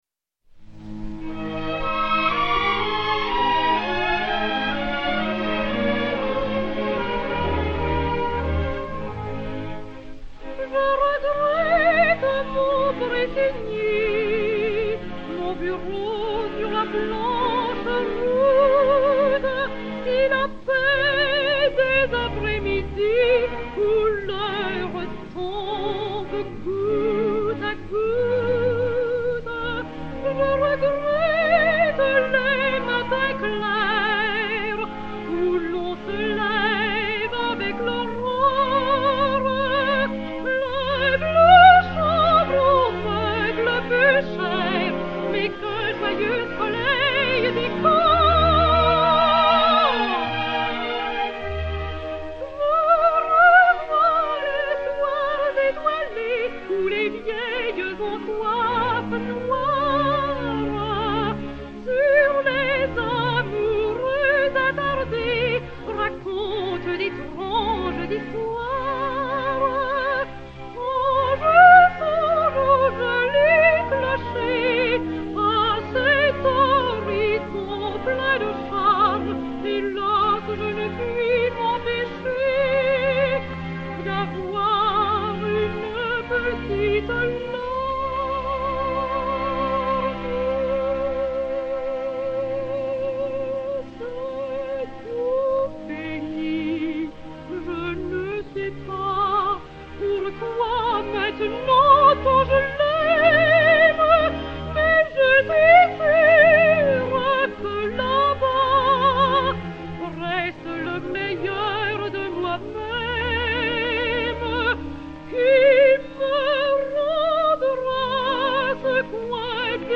Acte III. Romance "Je regrette mon Pressigny"
Fanély Revoil (Suzanne) et Orchestre dir Marcel Cariven
enr. vers 1934